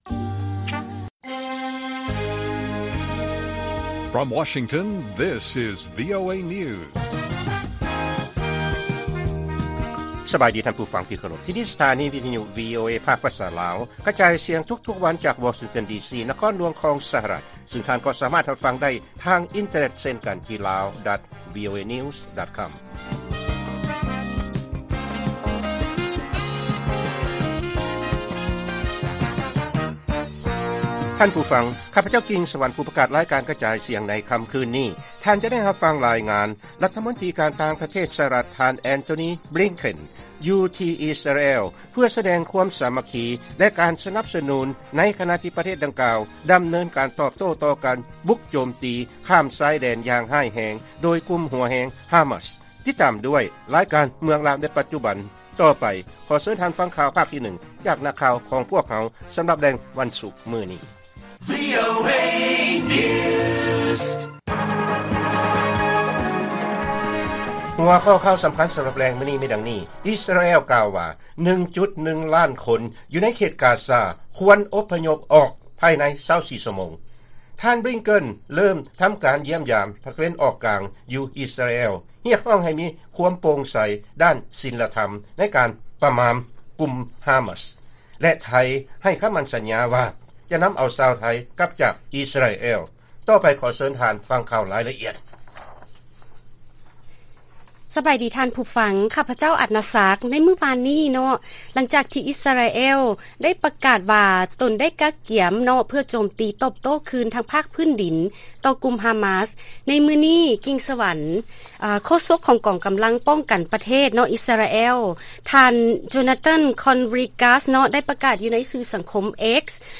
ລາຍການກະຈາຍສຽງຂອງວີໂອເອ ລາວ: ອິສຣາແອລ ກ່າວວ່າ 1.1 ລ້ານຄົນ ຢູ່ໃນເຂດກາຊາ ຄວນອົບພະຍົບອອກ ພາຍໃນ 24 ຊົ່ວໂມງ